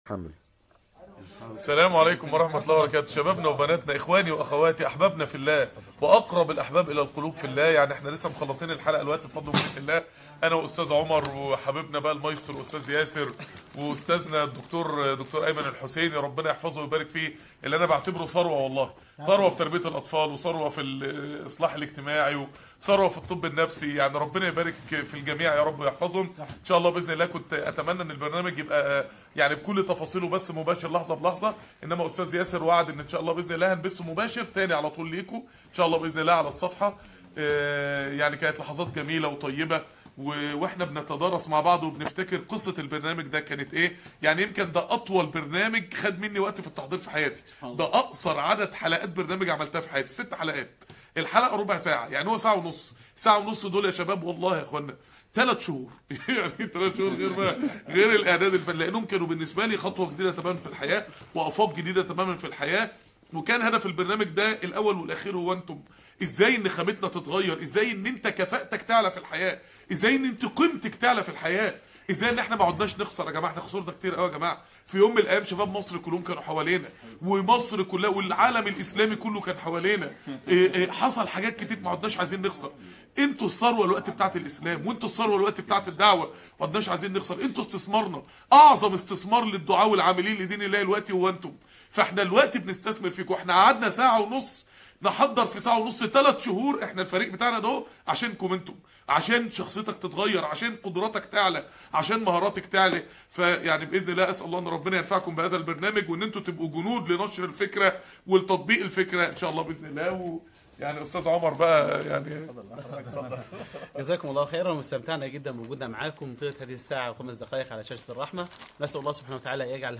من داخل استوديو قناة الرحمة .. كلمة أخيرة بعد انتهاء الحلقة